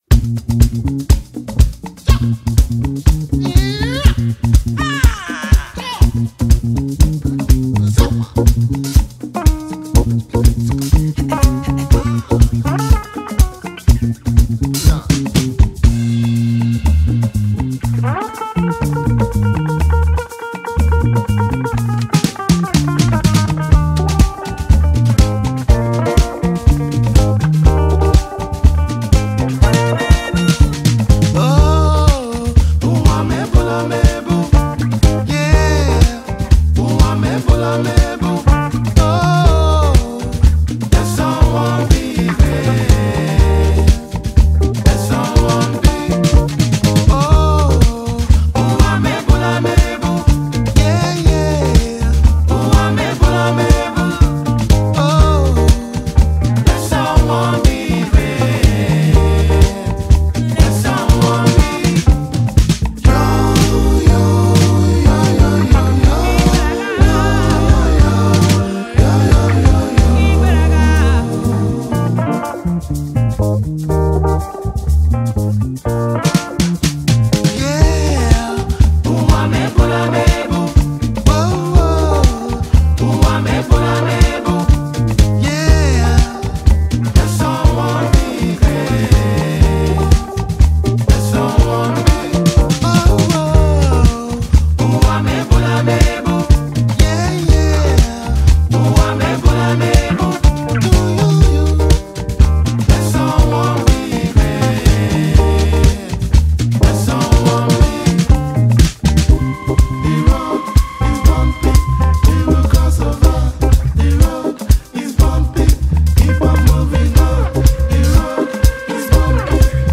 Talented Nigerian duo singer and songwriter